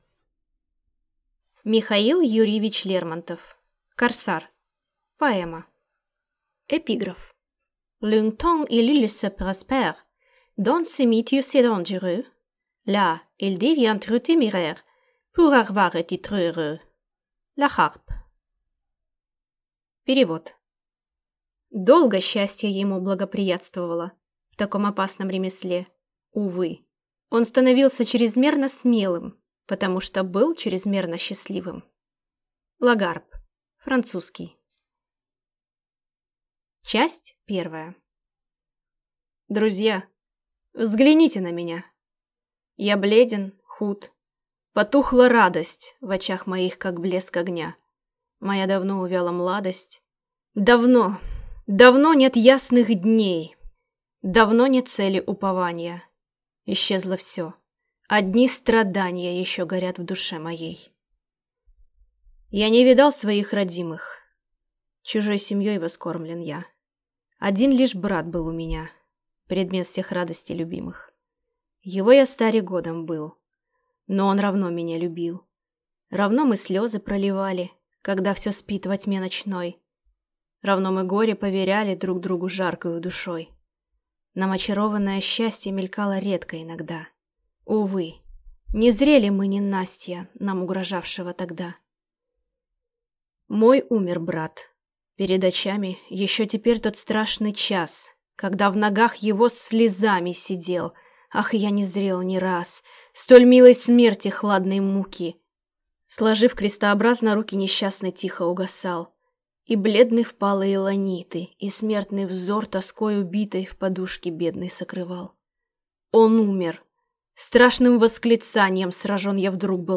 Аудиокнига Корсар | Библиотека аудиокниг
Прослушать и бесплатно скачать фрагмент аудиокниги